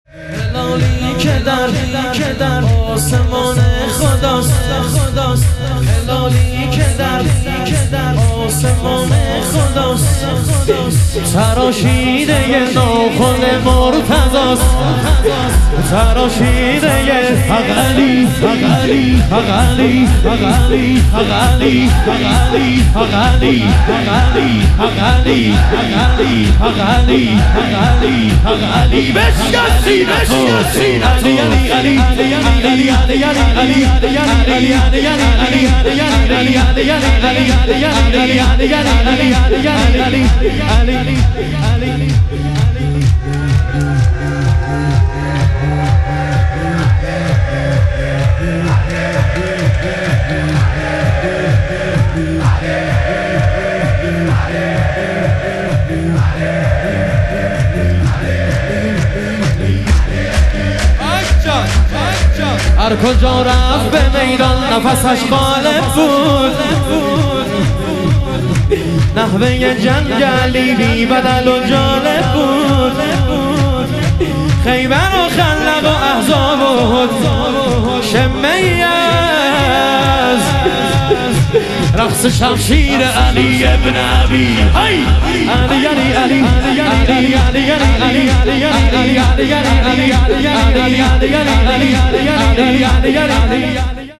شهادت حضرت مسلم علیه السلام - شور